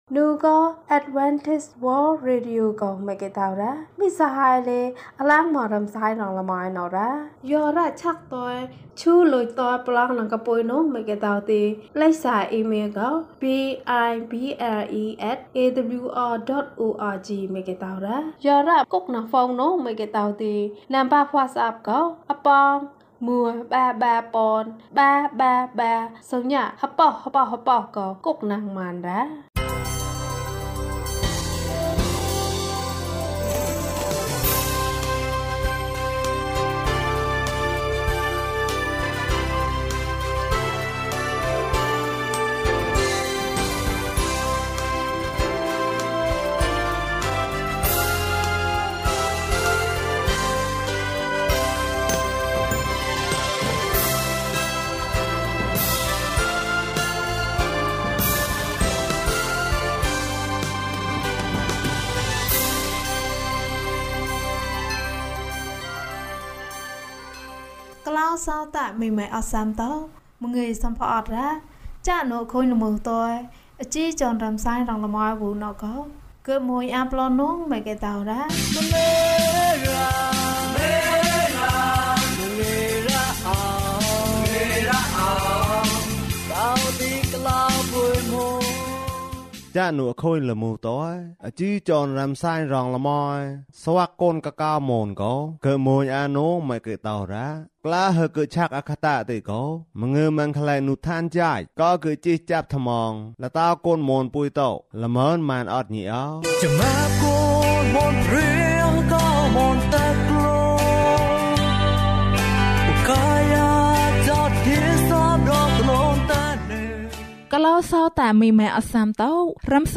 ကောင်လေးနှင့်ခွေးကလေး။၀၂ ကျန်းမာခြင်းအကြောင်းအရာ။ ဓမ္မသီချင်း။ တရားဒေသနာ။